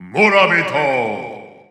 The announcer saying Villager's name in Japanese and Chinese releases of Super Smash Bros. 4 and Super Smash Bros. Ultimate.
Villager_Japanese_Announcer_SSB4-SSBU.wav